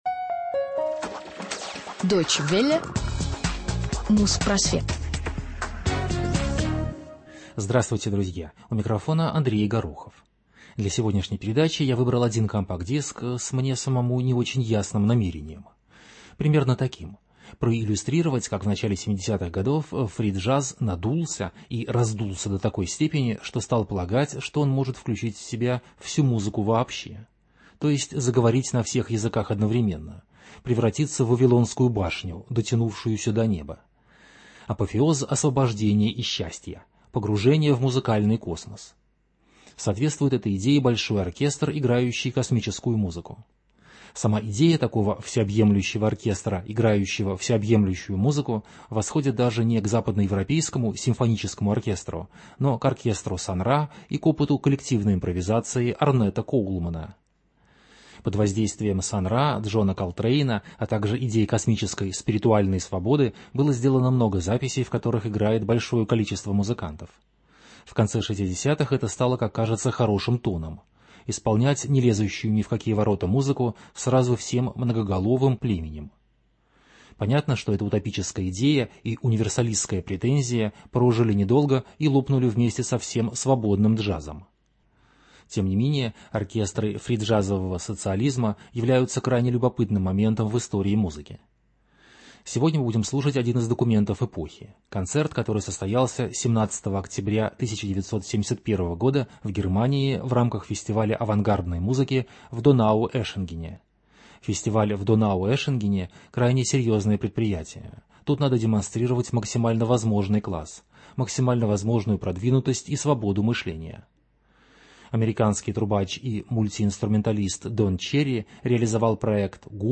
Фриджаз, этноджаз, академ.авангард, 1971.